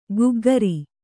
♪ guggari